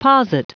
Prononciation du mot posit en anglais (fichier audio)
Prononciation du mot : posit